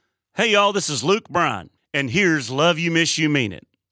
LINER Luke Bryan (LYMYMI) 1